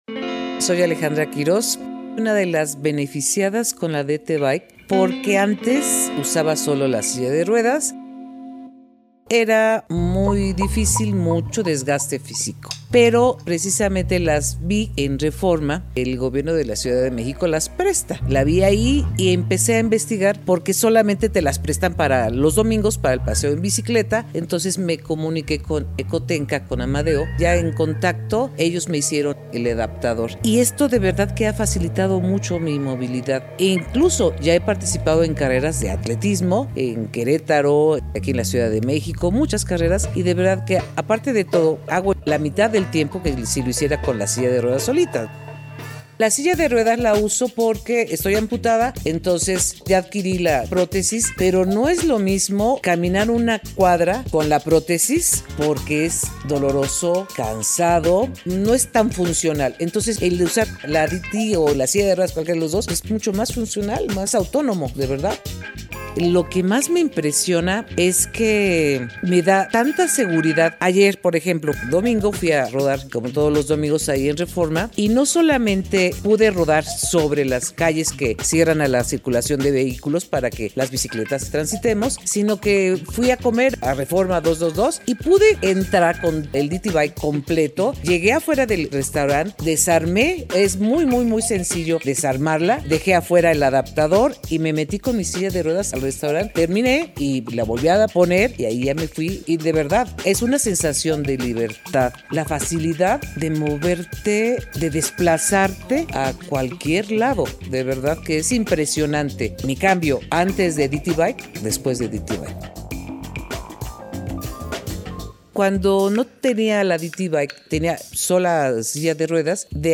ENTREVISTA CON BICITLAN RADIO